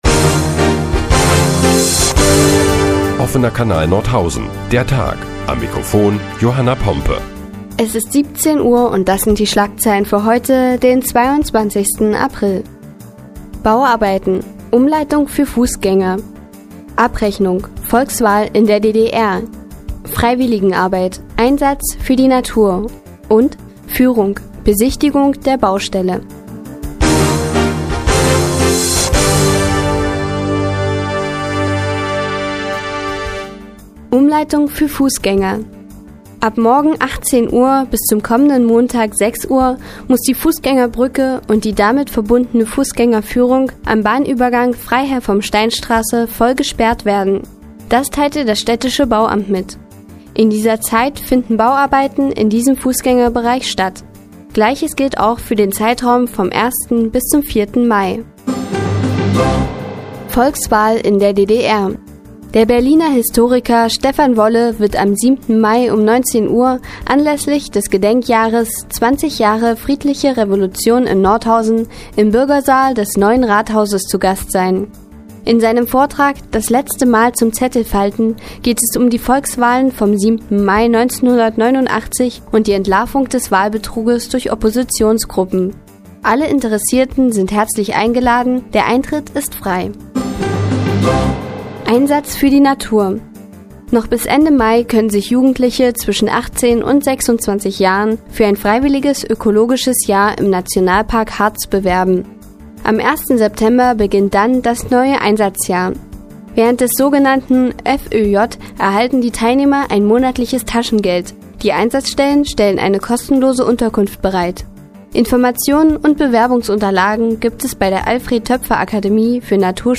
Die tägliche Nachrichtensendung des OKN ist nun auch in der nnz zu hören. Heute geht es unter anderem um Bauarbeiten und Führungen in der Freiherr-vom-Stein-Straße und einem Aufruf zur Freiwilligenarbeit in der Natur.